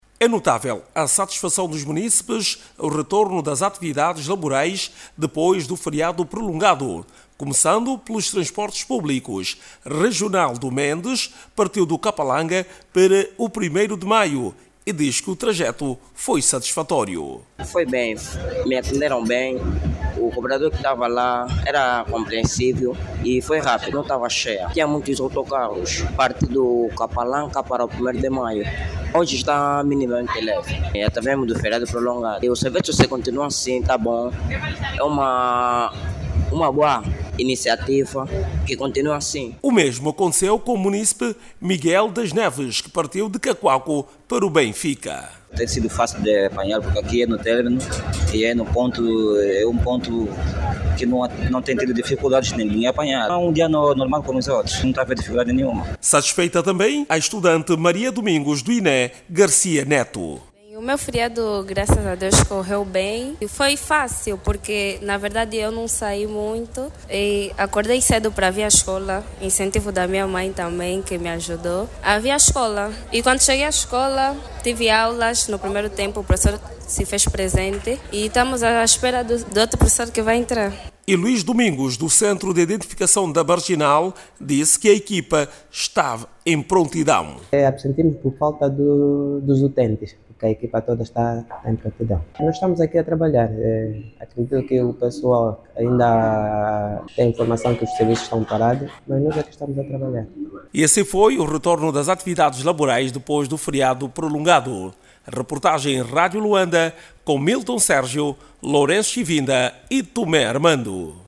Depois do fim-de-semana prolongado, os Luandenses, voltaram as suas actividades laborais e escolares sem constrangimentos por tudo o territorio da cidade capital. Segundo uma ronda feita pela rádio Luanda, trabalhadores e estudantes de varias instituições compareceram aos postos da actividades.